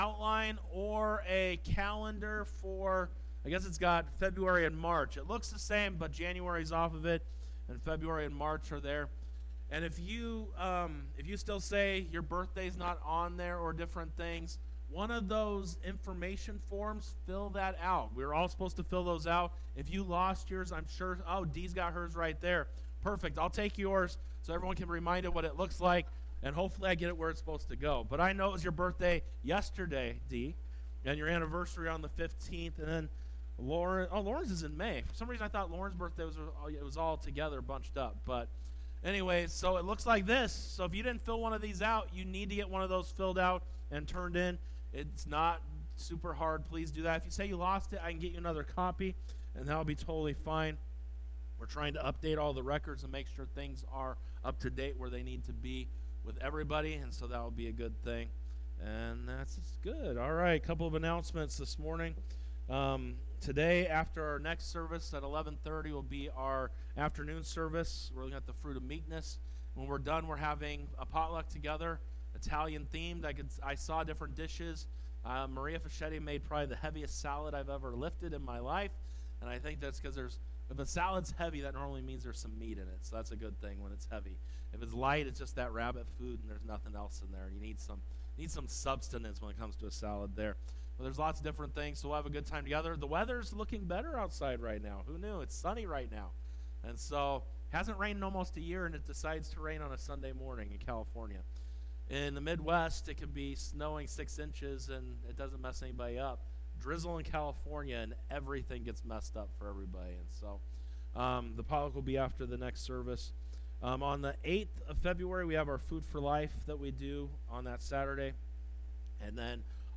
1.26.25 Sunday Worship Service, Living with Intention Part 4